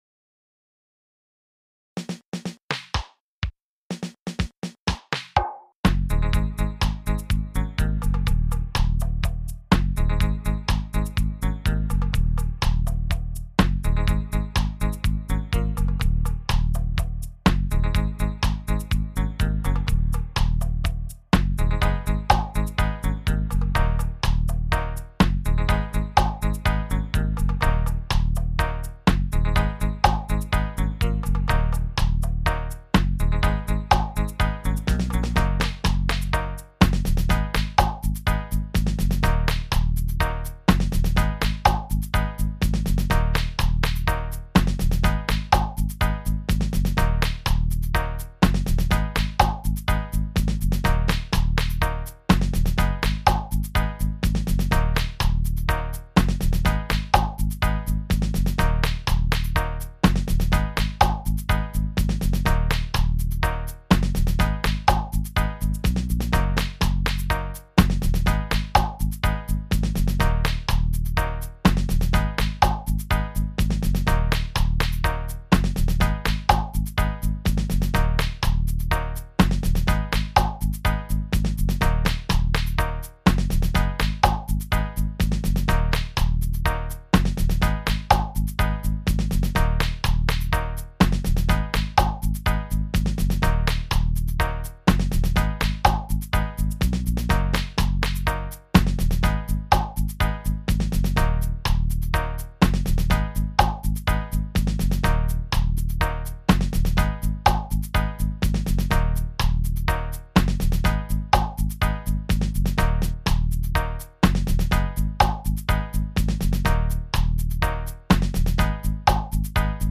Straight from computer , not mixed !